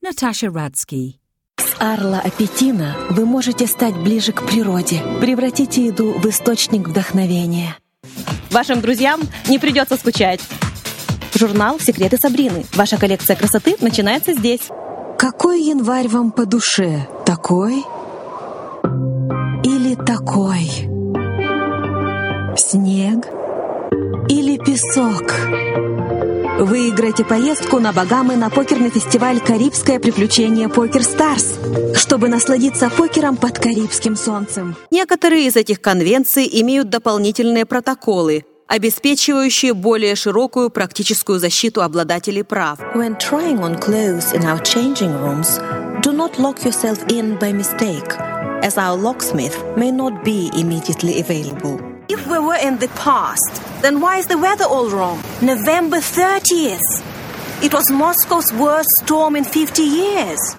Russian Ukrainian voiceover artist: contact her agent direct for female Russian and Ukrainian voice overs, recordings and session work.